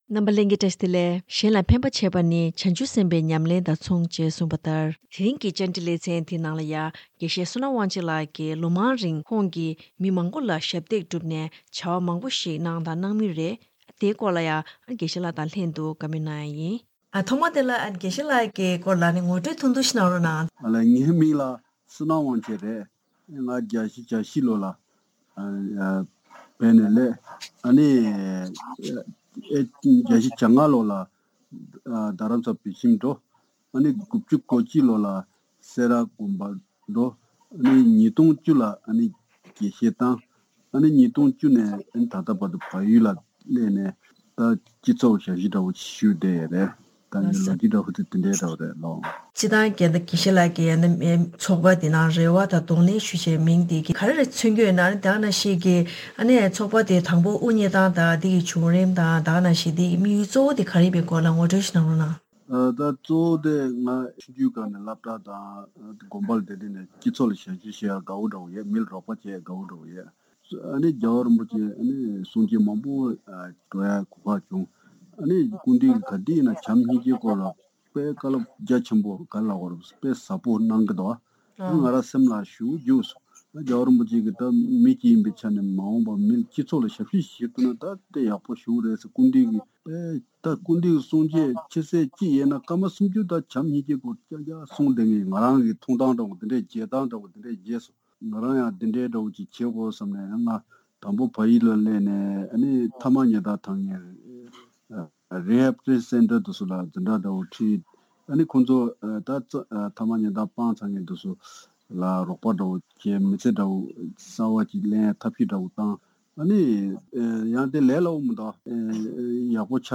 ཞིབ་ཕྲ་བཀའ་འདྲི་ཞུས་པ་ཞིག་གསན་རོགས་གནང་།